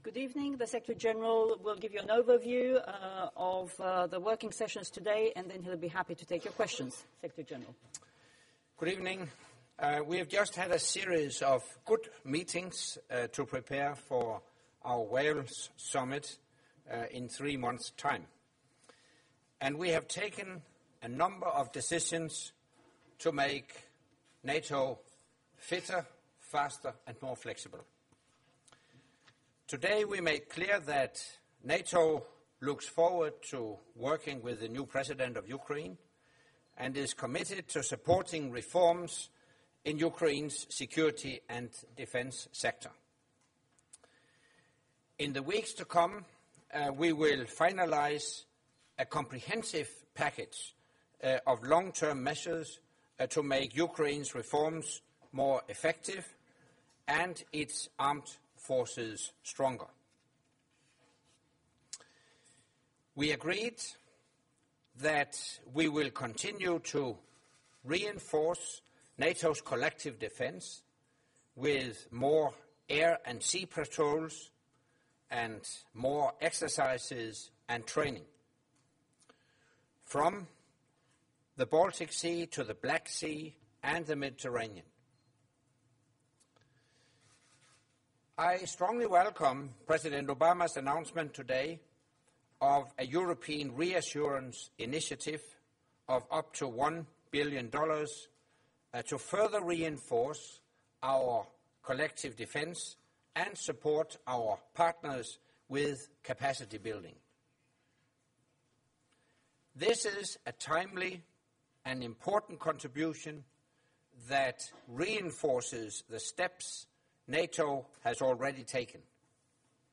Press conference by NATO Secretary General Anders Fogh Rasmussen following the first day of meetings of NATO Defence Ministers